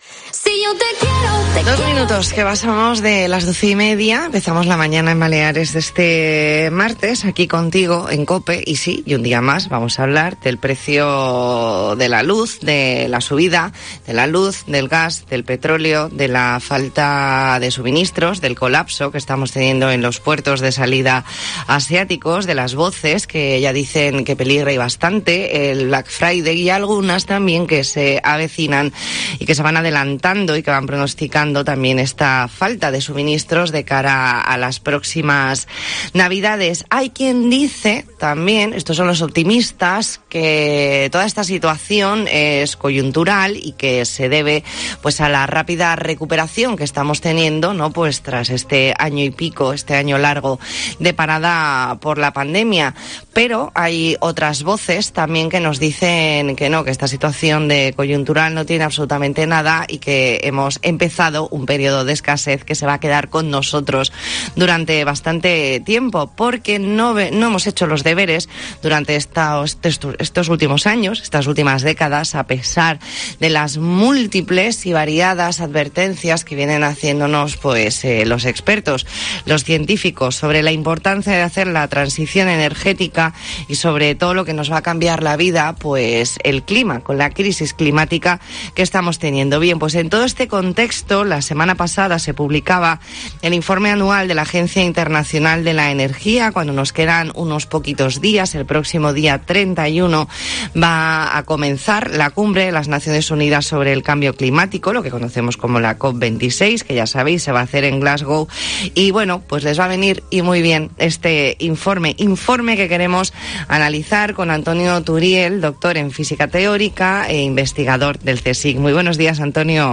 Entrevista en La Mañana en COPE Más Mallorca, martes 19 de octubre de 2021.